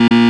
failure1.wav